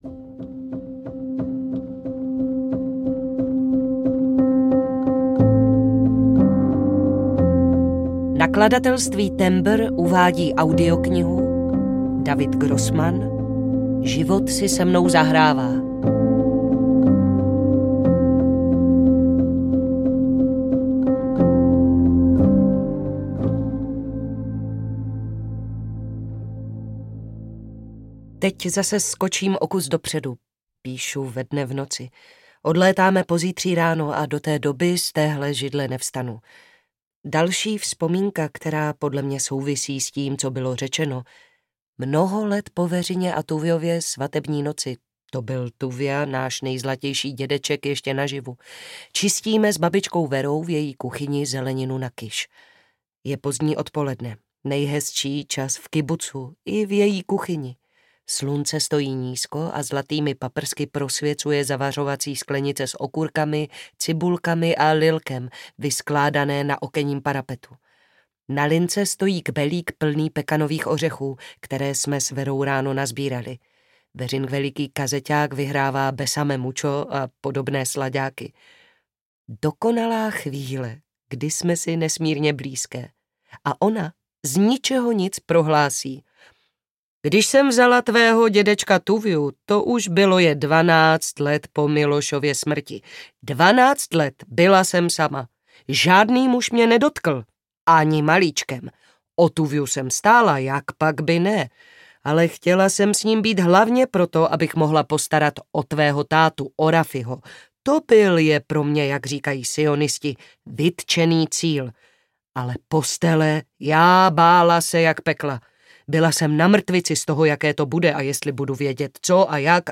Život si se mnou zahrává audiokniha
Ukázka z knihy